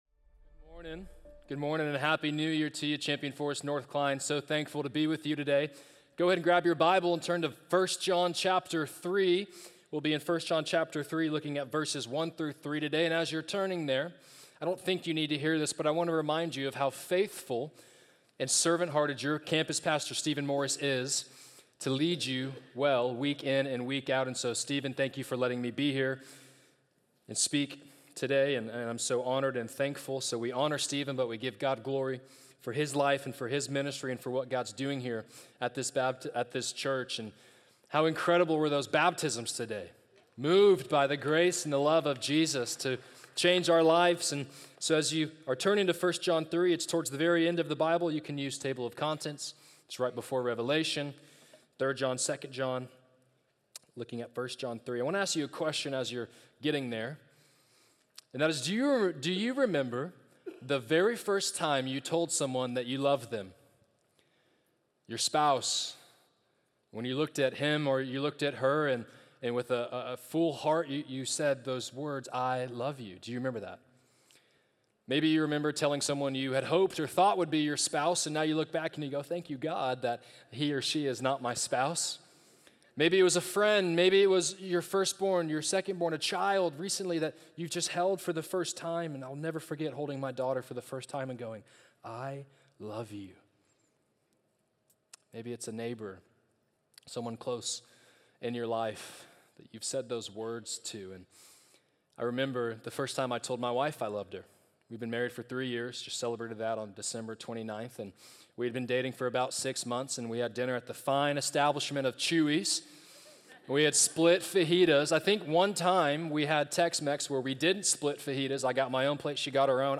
Wednesday Sermons – Media Player